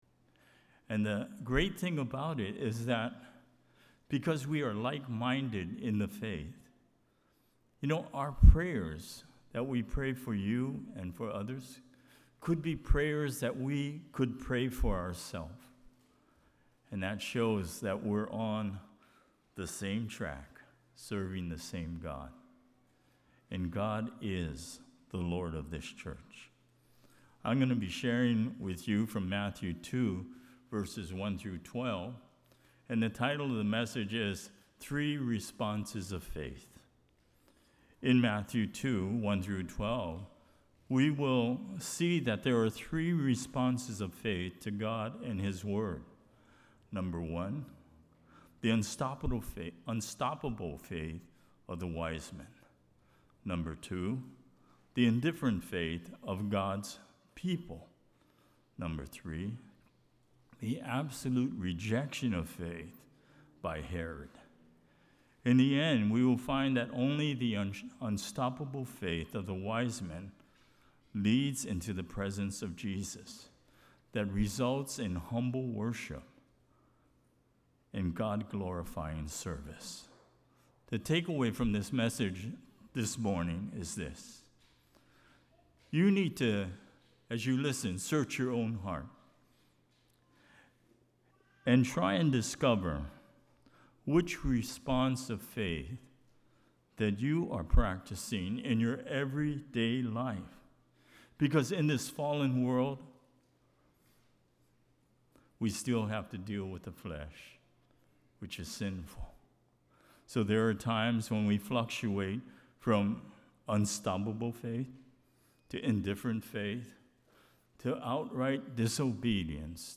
Kahului Baptist Church Sermons | Kahului Baptist Church